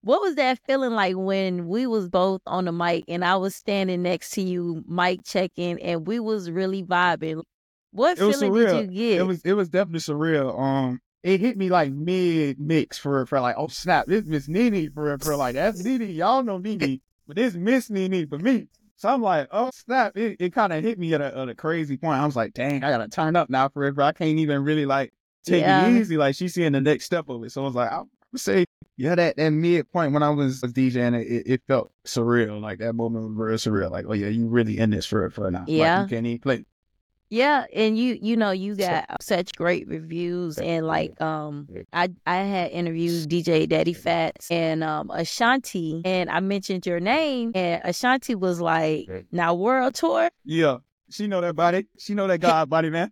In this engaging conversation